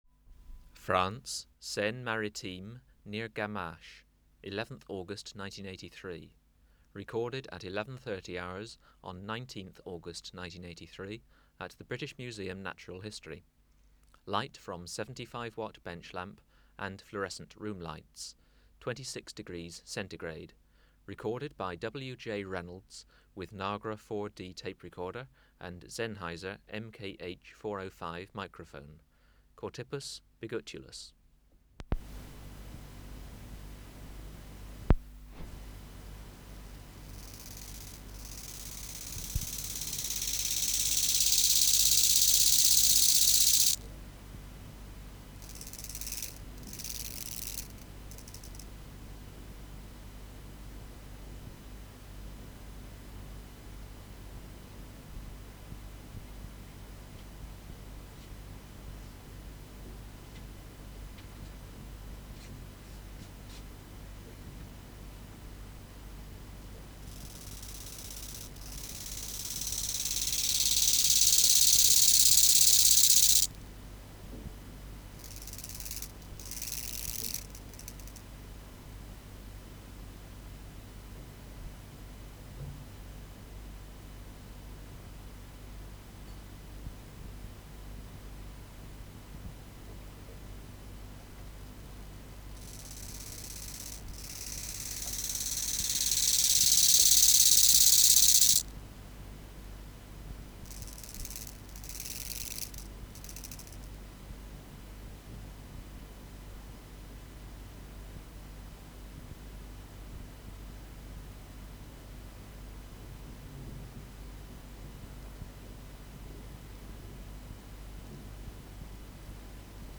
407:10 Chorthippus biguttulus (493a) | BioAcoustica
Recording Location: BMNH Acoustic Laboratory
Reference Signal: 1 kHz for 10 s
Substrate/Cage: Small recording cage Biotic Factors / Experimental Conditions: Courtship, male next to female
Microphone & Power Supply: Sennheiser MKH 405 Distance from Subject (cm): 10 Filter: Low Pass, 24 dB per octave. Corner frequency 20 Hz